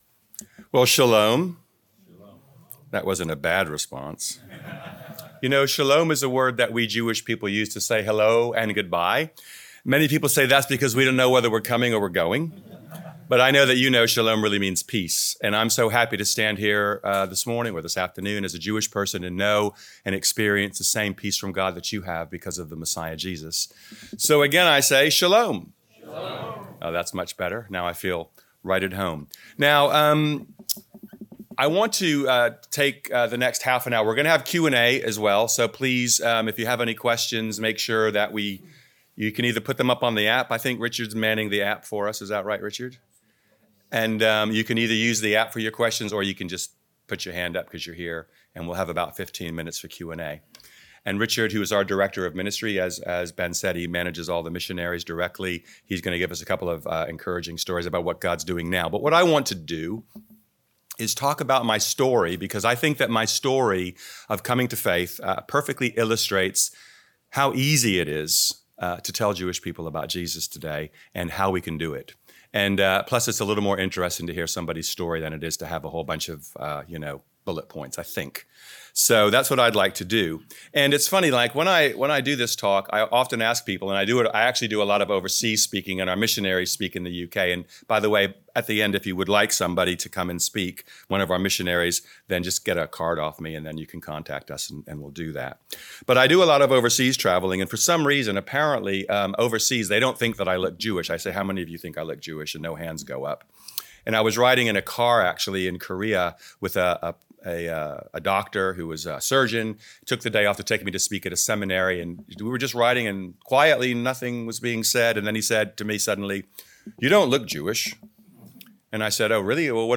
51 second listen Series: Leaders' Conference 2025 Theme